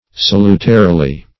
[1913 Webster] -- Sal"u*ta*ri*ly, adv. --